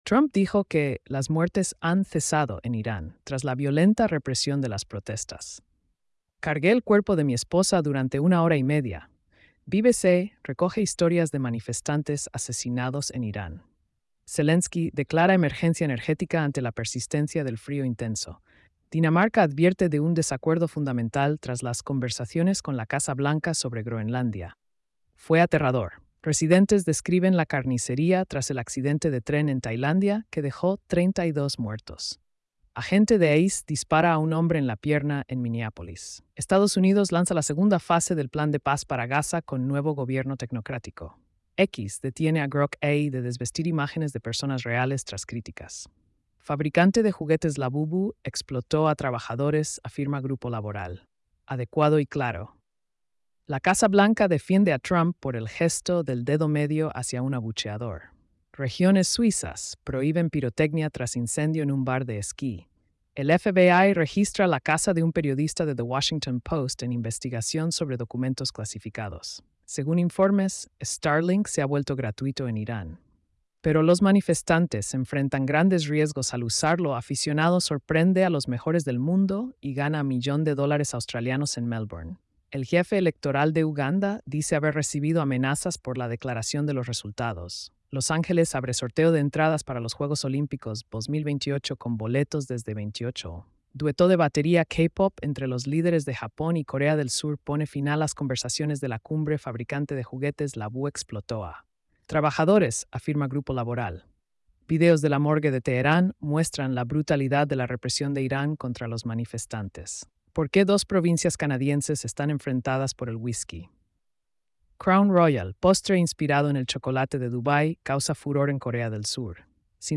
🎧 Resumen de noticias diarias.